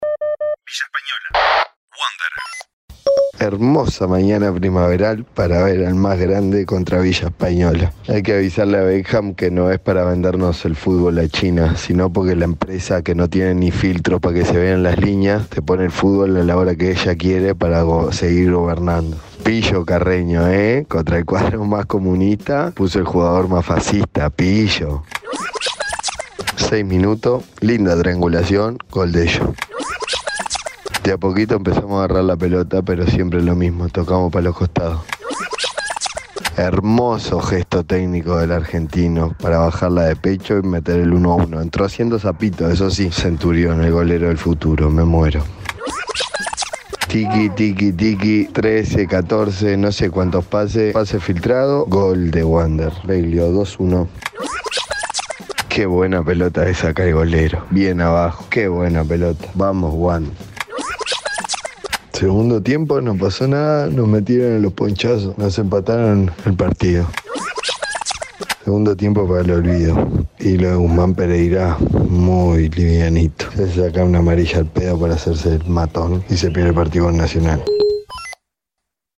Los partidos de la sexta fecha del Torneo Clausura del fútbol uruguayo vistos y comentados desde las tribunas.